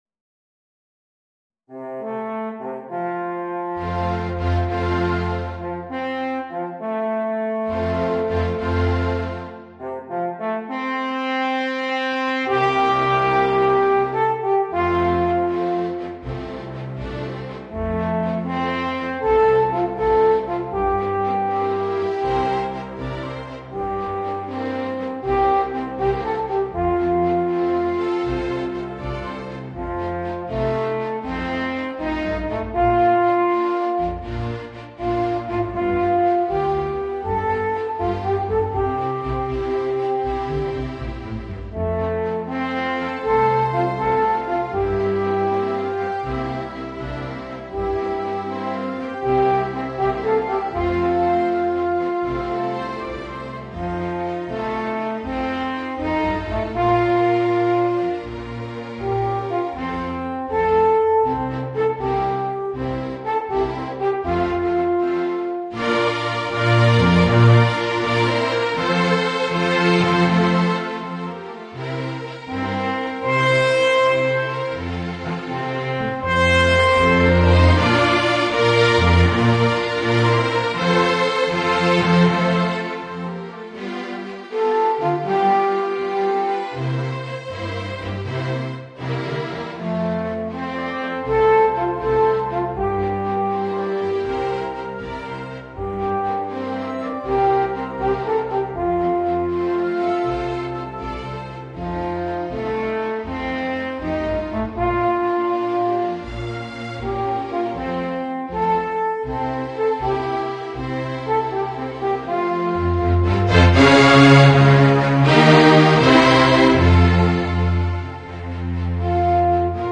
Gattung: Alphorn in F & Orchestra
Besetzung: Sinfonieorchester